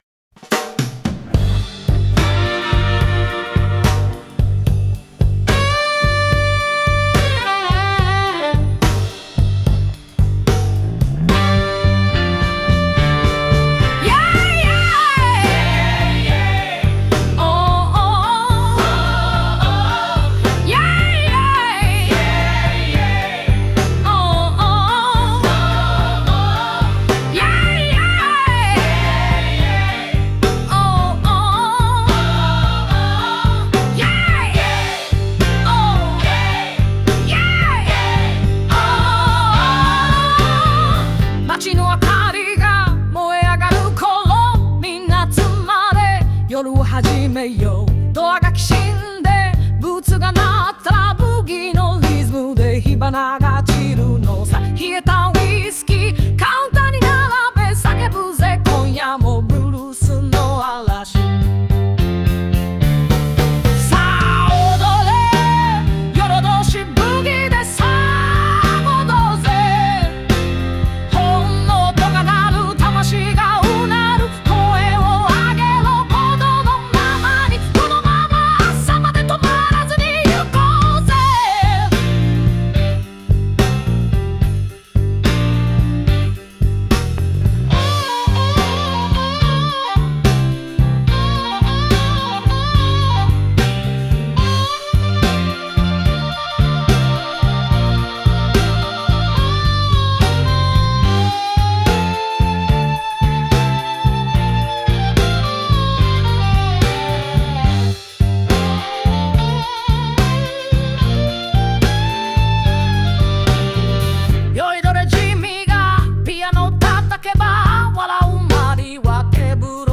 この楽曲は、夜の街を舞台に、ブギウギとブルースが交差する熱狂の宴を描いた一曲。